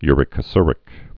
(yrĭ-kə-srĭk)